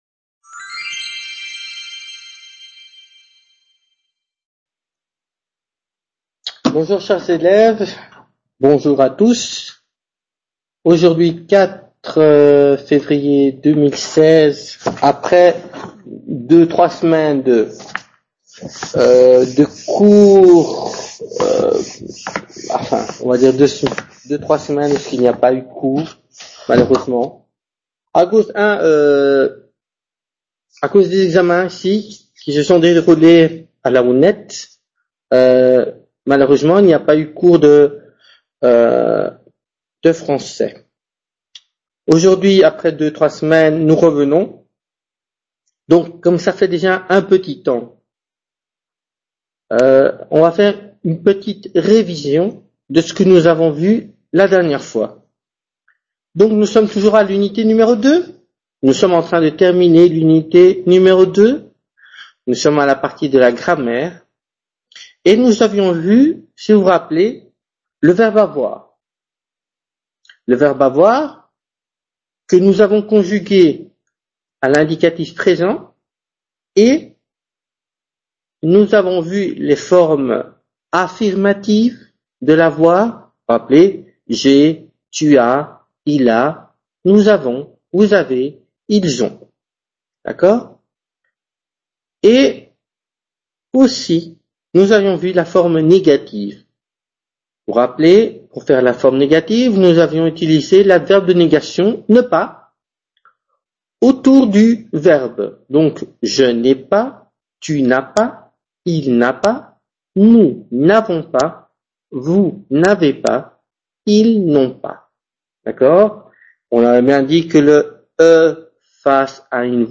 Clase de Francés Nivel Debutante 4/02/2016